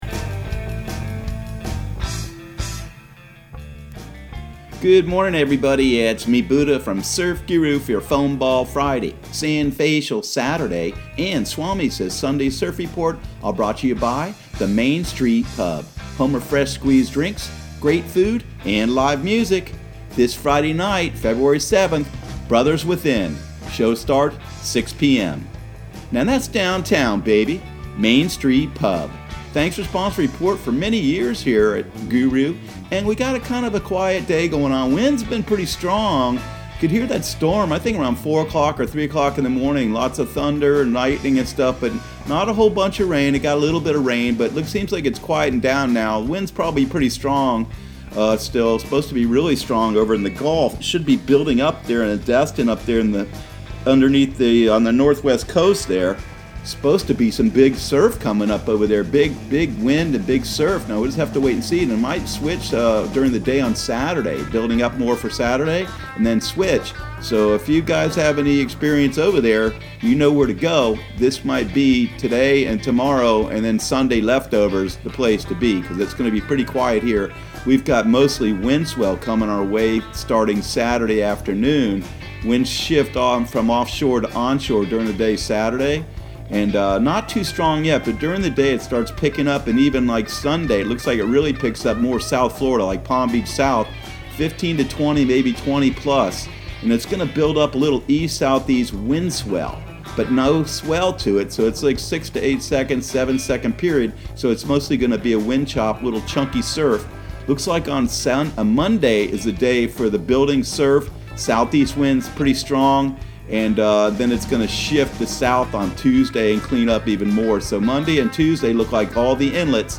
Surf Guru Surf Report and Forecast 02/07/2020 Audio surf report and surf forecast on February 07 for Central Florida and the Southeast.